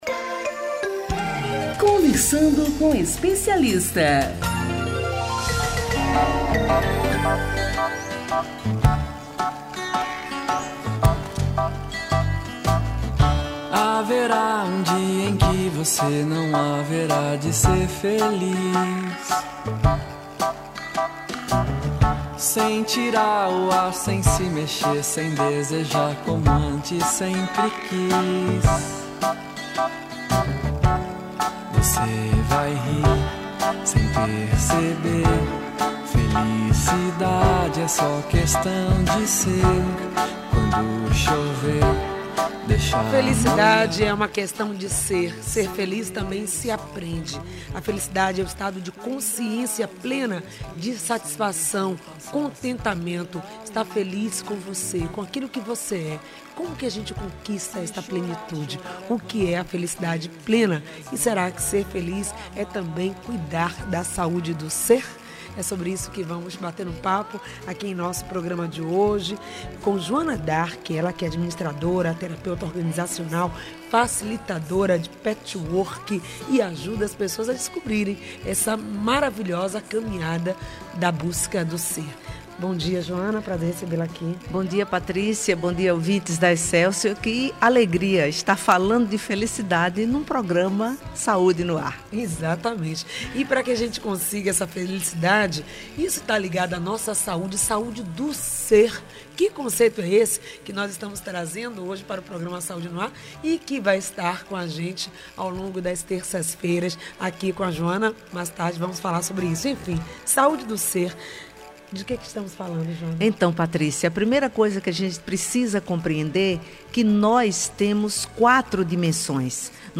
O programa exibido pela Rádio AM 840(em 10.05.16 das 8 às 9h) .